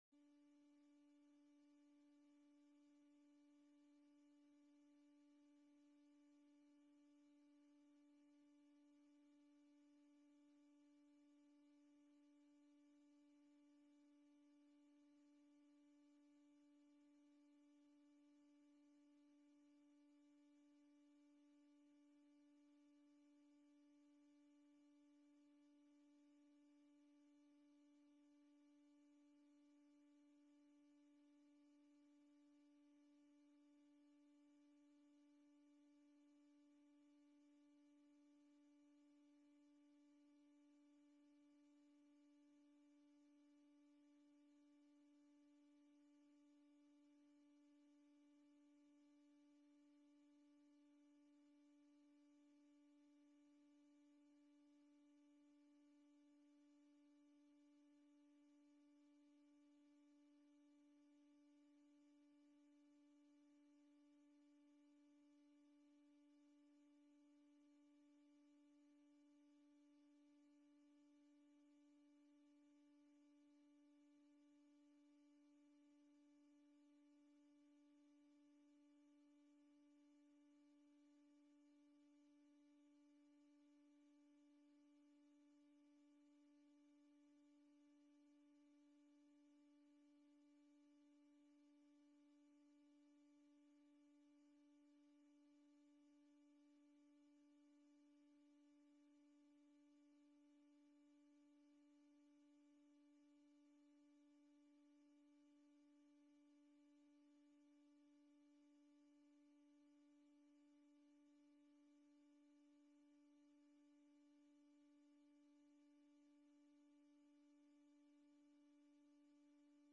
Agenda Nieuwegein - Raadsvergadering donderdag 23 april 2020 20:00 - 23:00 - iBabs Publieksportaal
Online, te volgen via livestreaming
Oproeping voor het bijwonen van de openbare raadsvergadering, welke zal worden gehouden via livestreaming in het Stadshuis aan het Stadsplein 1 op donderdag 23 april 2020, aanvang 20.00 uur met mogelijke voortzetting op dinsdag 28 april 2020, aanvang 20.00 uur.